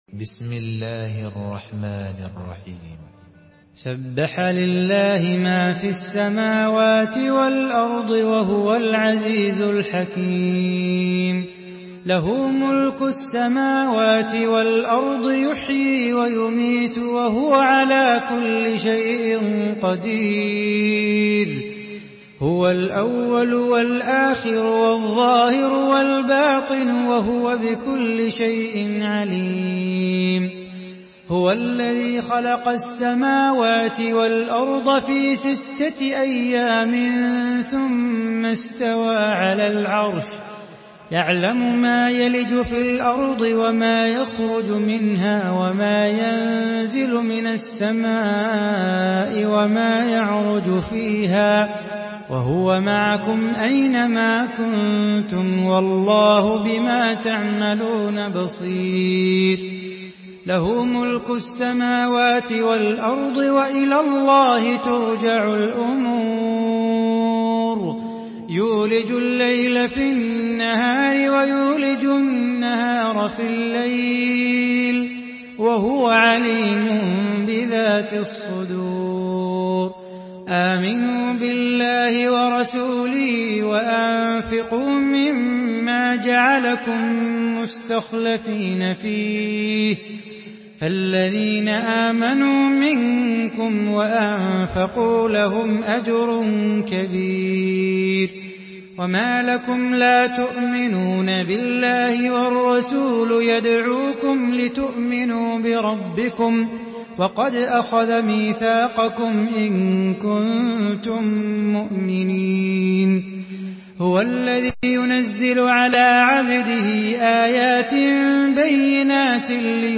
Surah Hadid MP3 by Salah Bukhatir in Hafs An Asim narration.
Murattal Hafs An Asim